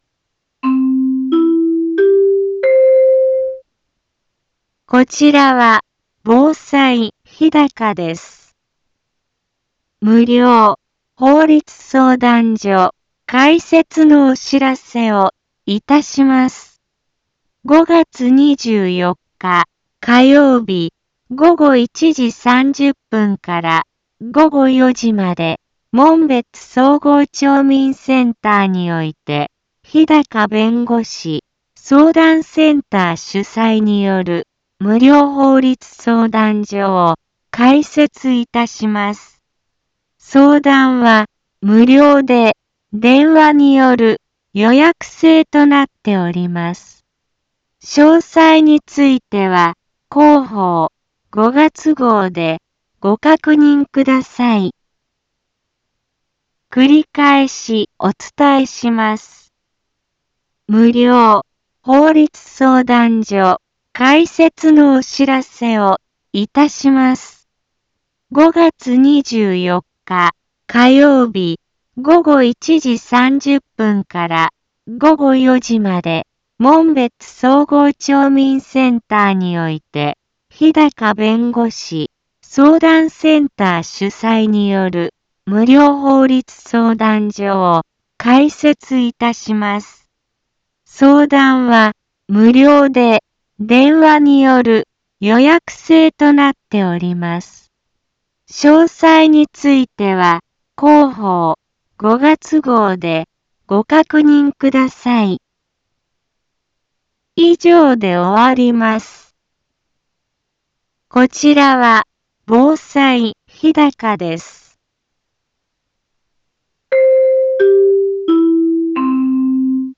Back Home 一般放送情報 音声放送 再生 一般放送情報 登録日時：2022-05-20 15:04:12 タイトル：無料法律相談会のお知らせ インフォメーション：こちらは防災日高です。 無料法律相談所開設のお知らせをいたします。